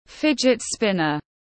Con quay giảm căng thẳng tiếng anh gọi là fidget spinner, phiên âm tiếng anh đọc là /ˈfɪdʒ.ɪt ˌspɪn.ər/